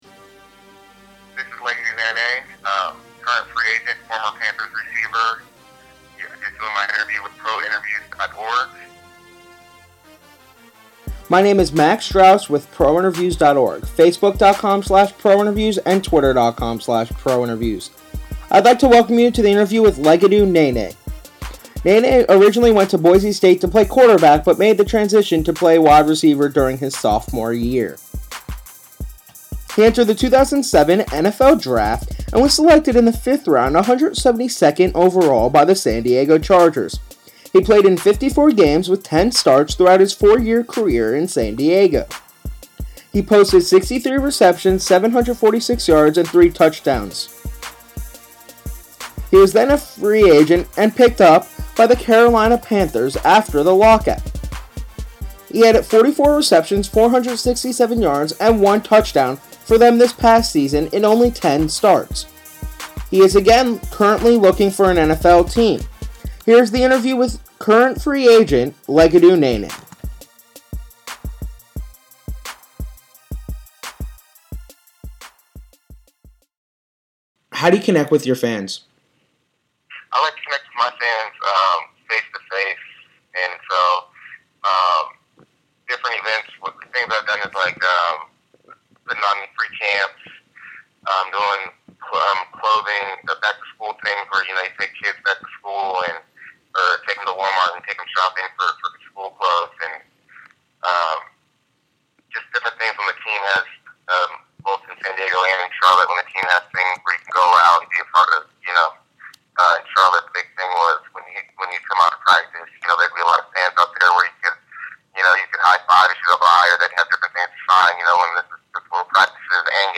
Free Agent WR, Legedu Naanee Interview
interview-with-legedu-naanee.mp3